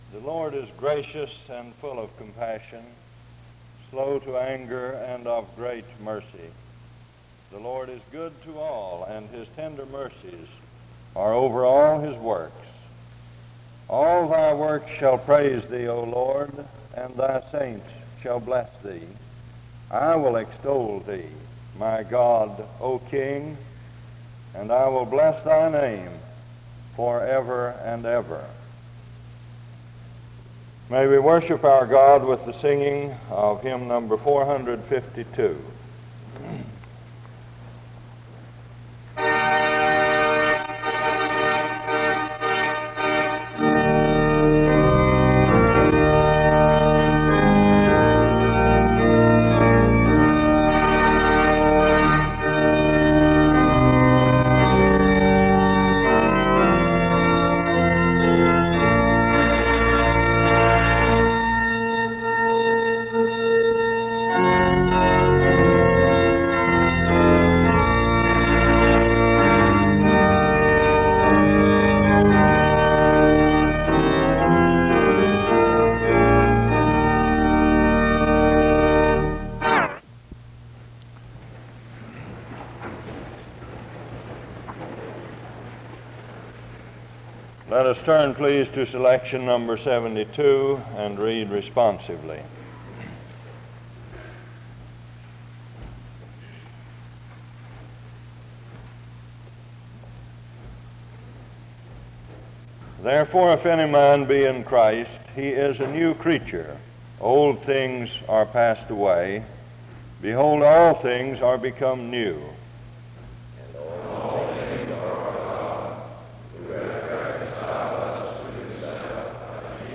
The service begins with the reading of scripture from 0:00-0:30. Music plays from 0:31-1:20. A responsive reading takes place from 1:27-3:34. A prayer is offered from 3:40-7:59. Special music plays from 8:12-11:18. An introduction to the speaker is from 11:27-15:00. Dr. Judd speaks from 15:03-59:08. Judd gives a testimony of his missionary efforts in China. Dr. Judd explains the nature of the communist movement. Closing music plays from 59:09-1:00:16.